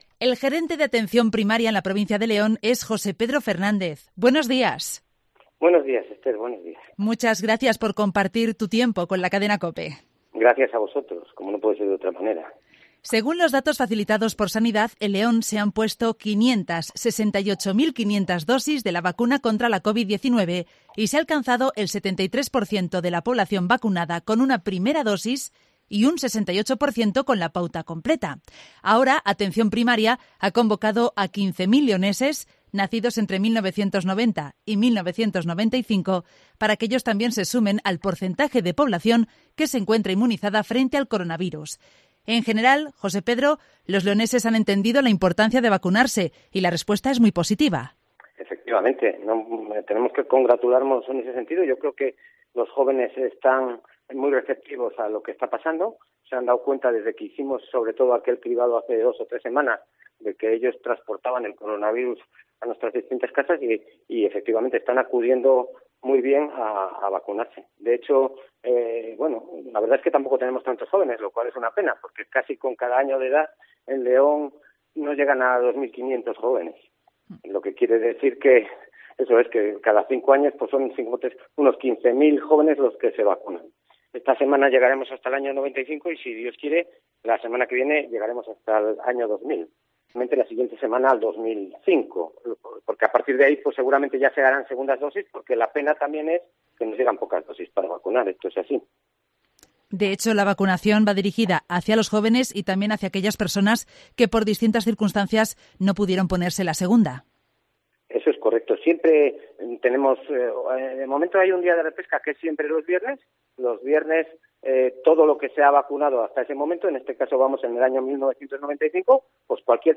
ha intervenido esta mañana en el programa local de Cope León para analizar la situación sanitaria en la provincia.